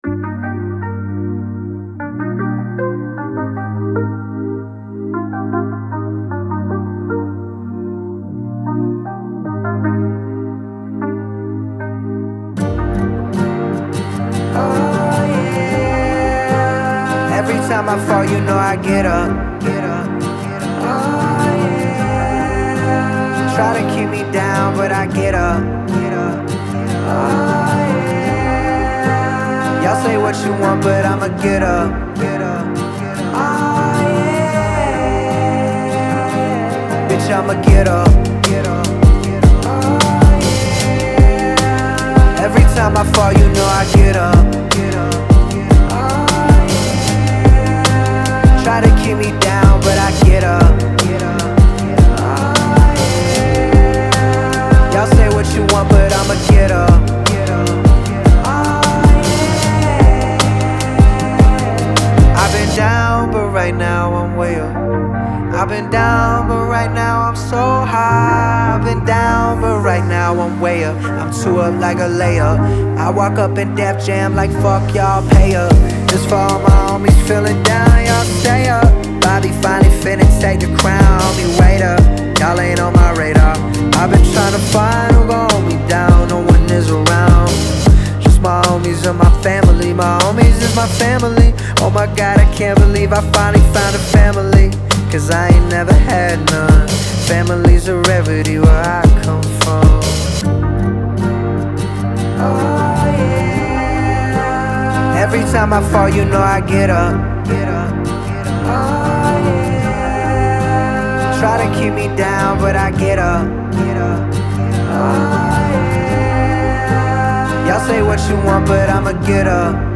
энергичная хип-хоп композиция